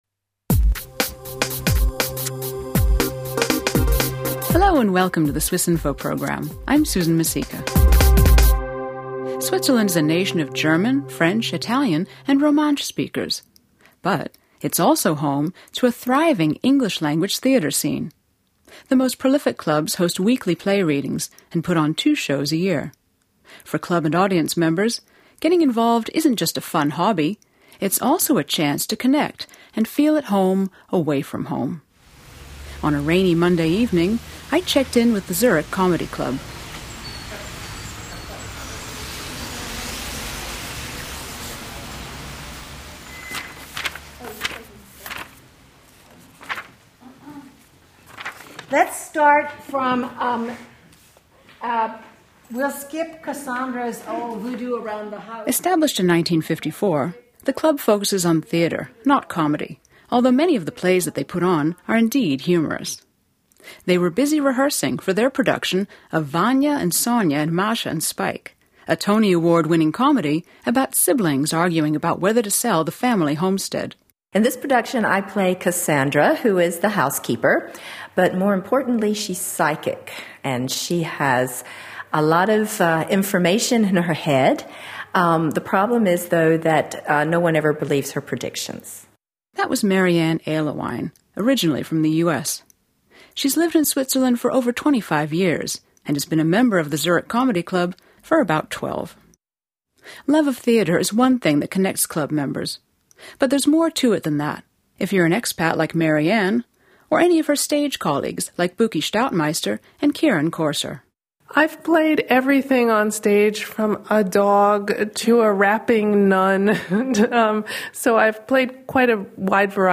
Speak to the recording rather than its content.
This podcast takes us to a performance in Bern as well as a play reading and rehearsal (pictured below) in Zurich.